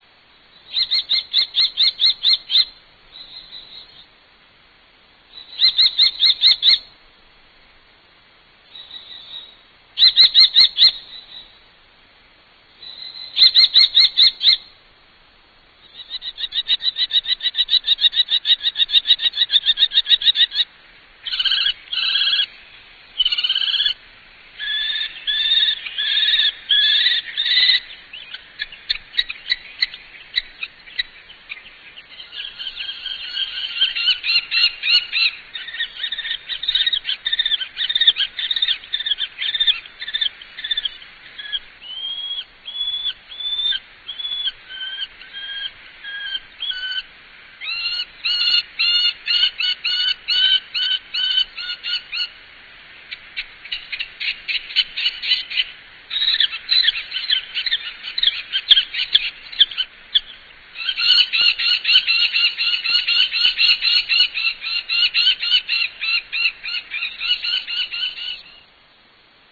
Turmfalke (Falco tinnunculus)
Common Kestrel
Stimme: durchdringend, helles »kikikikiki«. In der Nähe des Horstes leises, andauerndes »wriiiiih«.
kestrel.ra